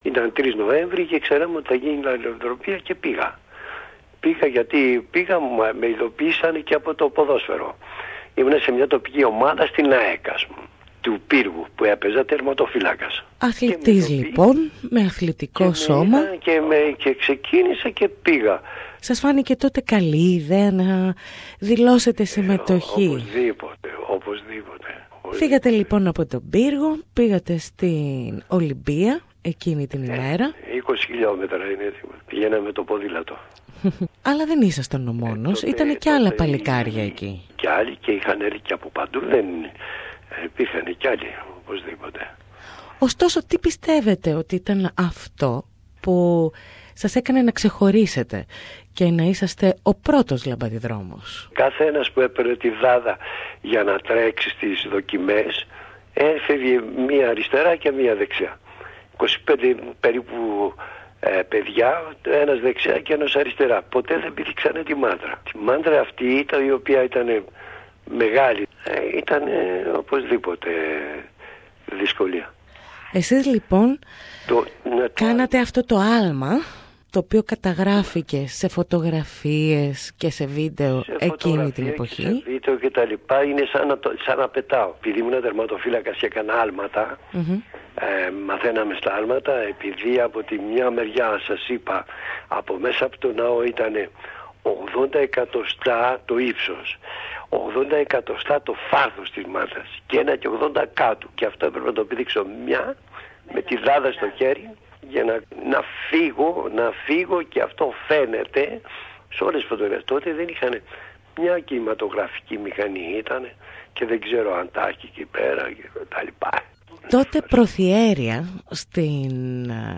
Ακούστε τη σπάνια συνέντευξη του 2015